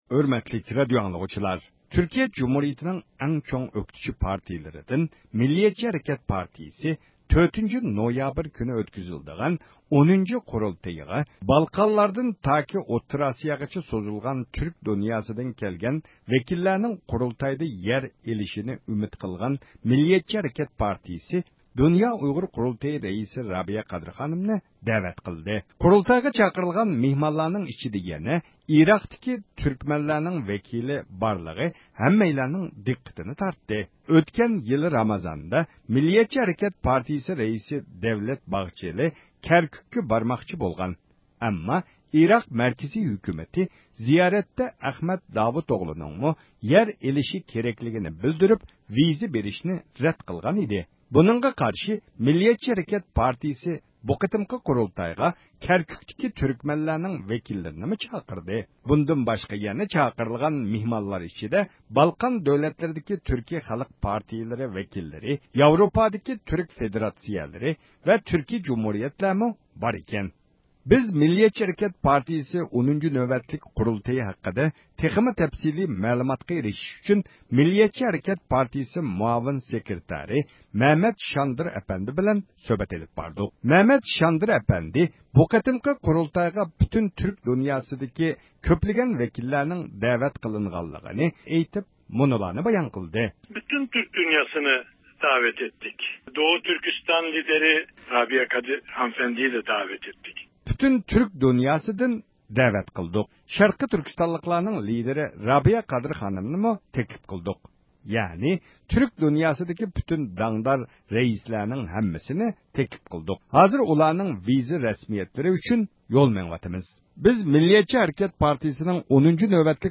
بىز «مىللەتچى ھەرىكەت پارتىيىسى» نىڭ 10-نۆۋەتلىك قۇرۇلتىيى ھەققىدە تېخىمۇ تەپسىلىي مەلۇماتقا ئېرىشىش ئۈچۈن مەزكۇر پارتىيىنىڭ مۇئاۋىن سېكرېتارى مەمەت شاندىر ئەپەندى بىلەن سۆھبەت ئېلىپ باردۇق.